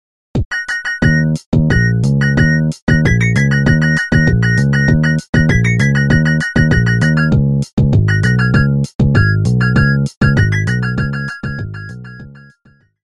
Nokia полифония. Зарубежные